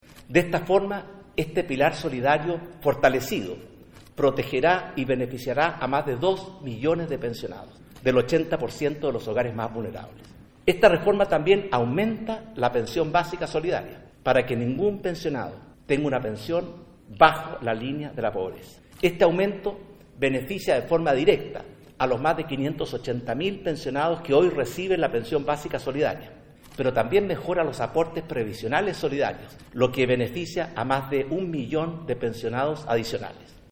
A través de una cadena nacional, el Mandatario dijo que le pondrán urgencia al proyecto e ingresarán una indicación que incluye la ampliación de la cobertura del Pilar Solidario, pasando desde el actual 60% de la población más vulnerable, a cubrir el 80% de la población del país. En esa línea señaló que “De esta forma, esta reforma se hace cargo de entregar protección social a 480 mil pensionadas y pensionados de clase media, que hoy no cuentan con apoyo del Estado y sólo dependen de su ahorro individual”.